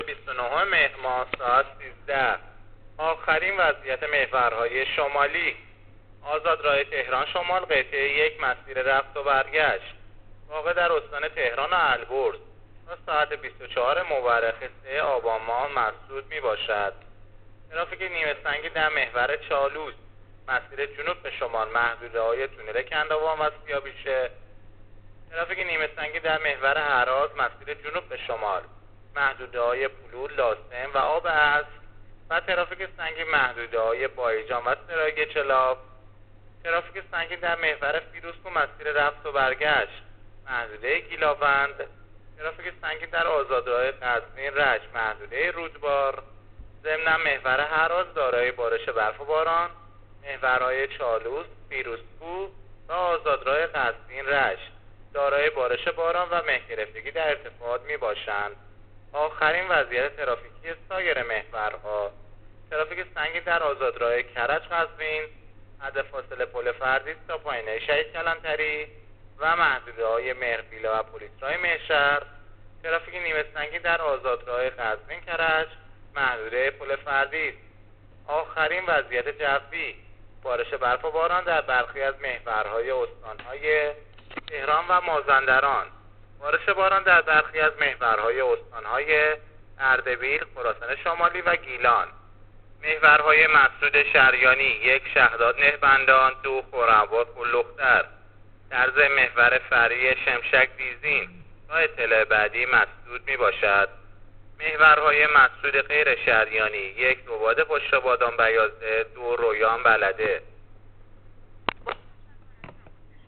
گزارش رادیو اینترنتی از آخرین وضعیت ترافیکی جاده‌ها تا ساعت ۱۳ بیست‌و نهم مهر؛